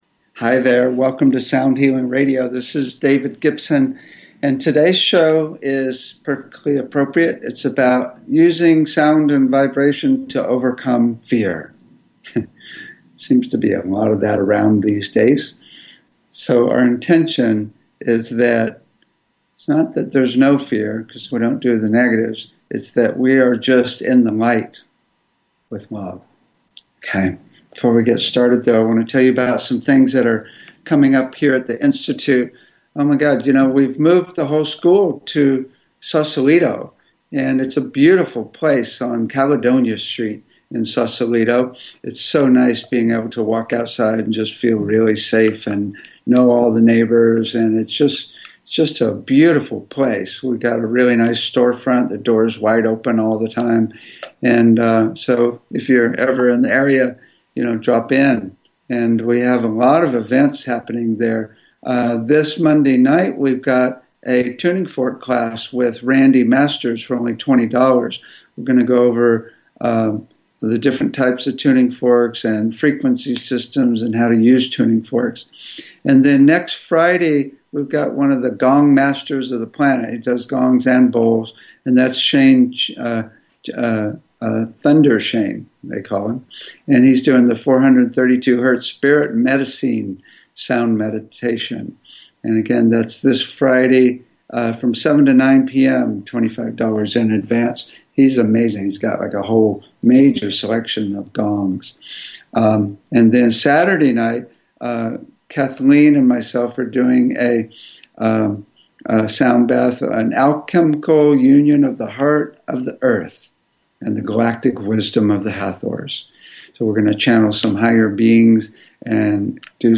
Talk Show Episode, Audio Podcast, Sound Healing and Using Sound and Vibration to Overcome Fear on , show guests , about Using Sound and Vibration to Overcome Fear, categorized as Education,Health & Lifestyle,Sound Healing,Philosophy,Physics & Metaphysics,Science,Self Help,Spiritual,Technology